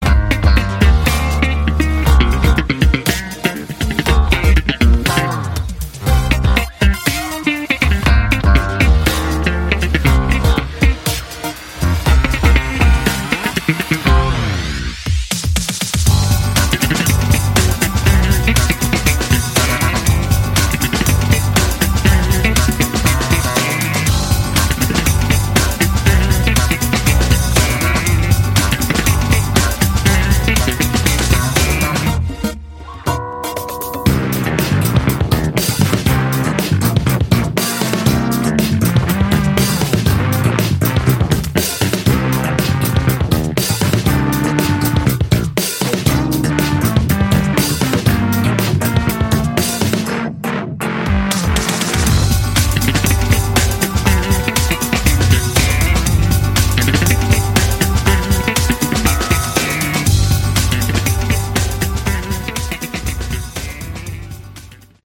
Category: Instrumental Hard Rock